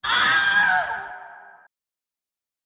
sfx_walla_kid_dying.wav